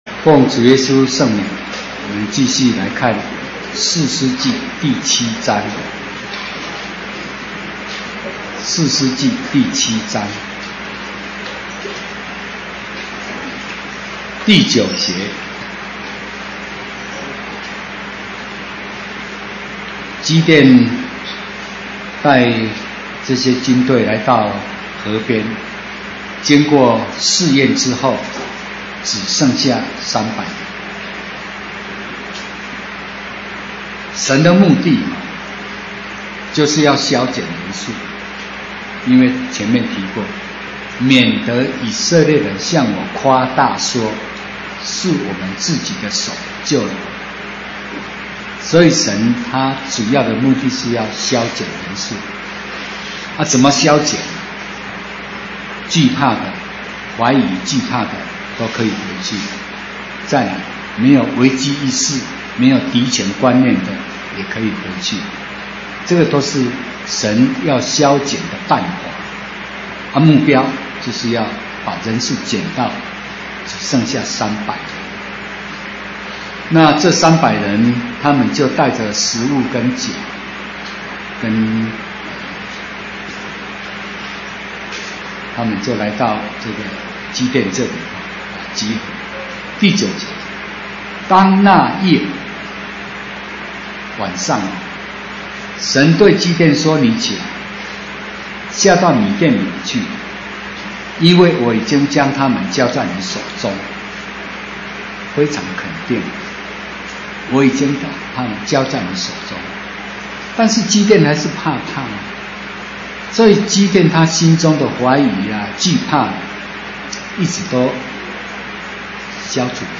講習會
地點 台灣總會 日期 02/14/2016 檔案下載 列印本頁 分享好友 意見反應 Series more » • 士師記 20-1 • 士師記 20-2 • 士師記 20-3 …